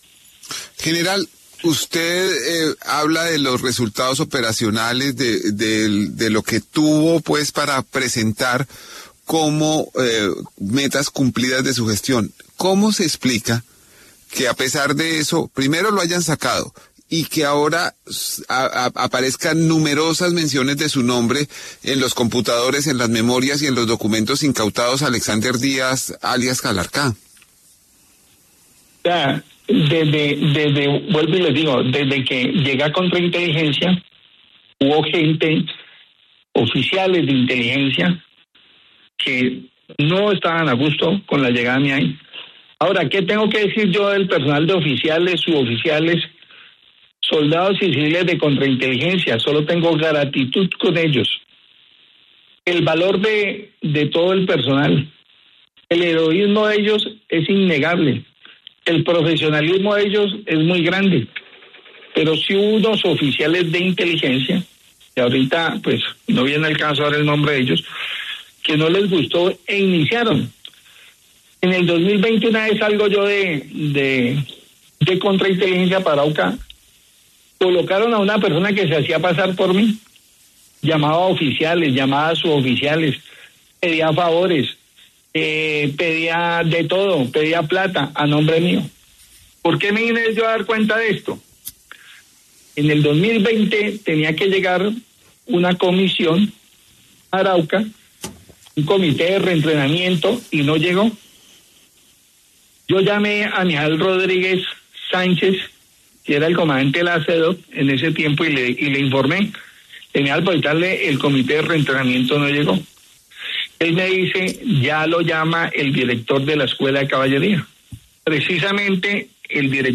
Habla el general Juan Miguel Huertas, señalado por vínculos con alias ‘Calarcá’